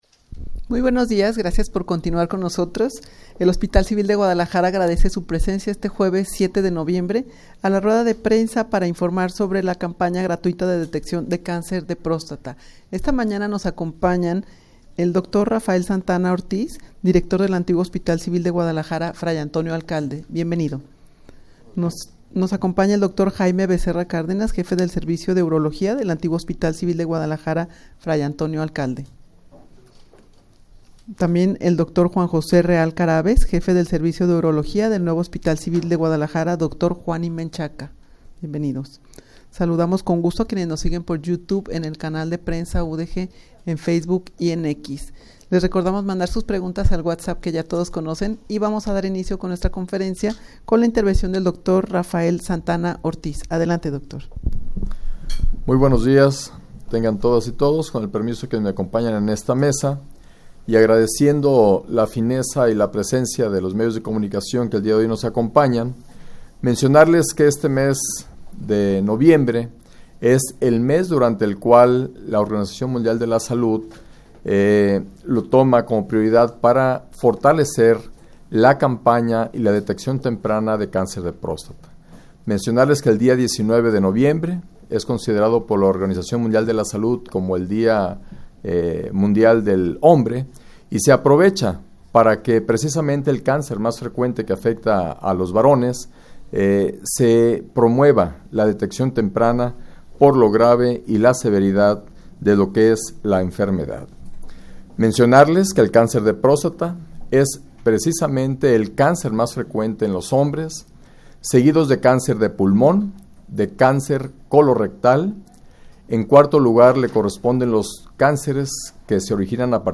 Audio de la Rueda de Prensa
rueda-de-prensa-para-informar-sobre-la-campana-gratuita-de-deteccion-de-cancer-de-prostata.mp3